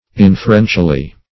inferentially - definition of inferentially - synonyms, pronunciation, spelling from Free Dictionary Search Result for " inferentially" : The Collaborative International Dictionary of English v.0.48: Inferentially \In`fer*en"tial*ly\, adv. By way of inference; using inference.